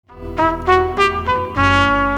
• Качество: 320, Stereo
спокойные
Саксофон